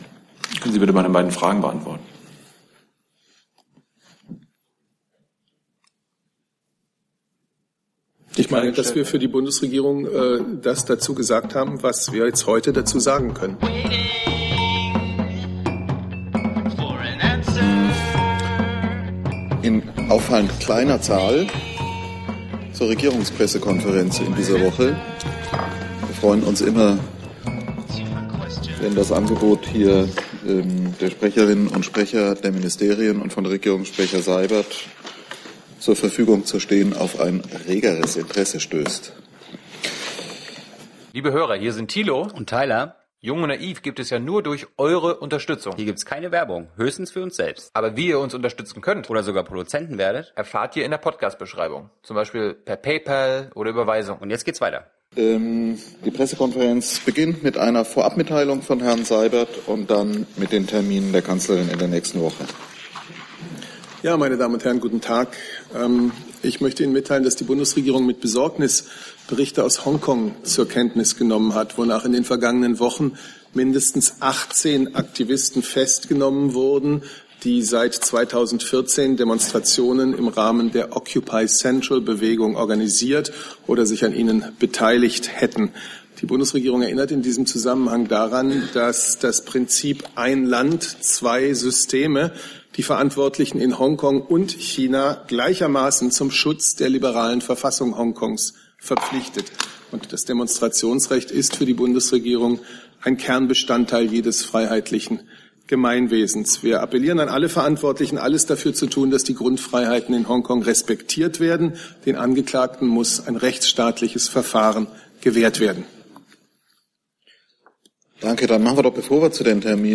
RegPK - 05.05.2017 - Regierungspressekonferenz ~ Neues aus der Bundespressekonferenz Podcast